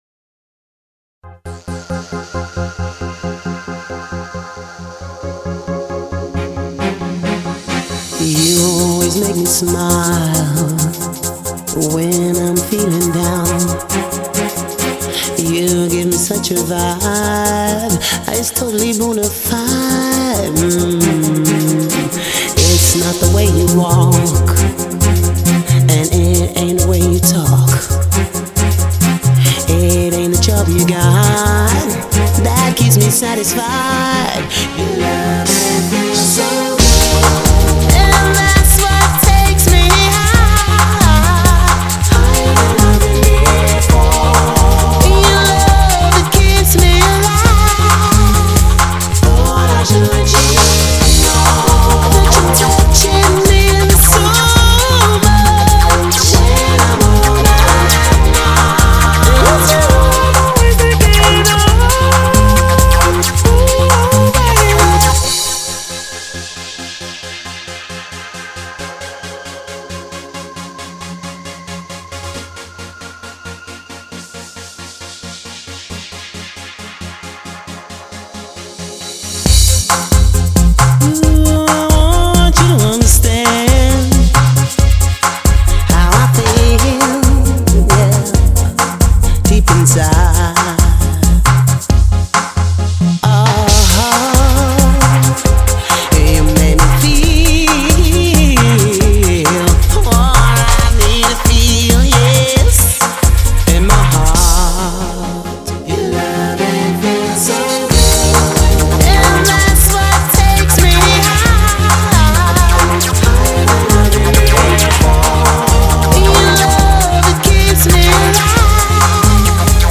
Eurodance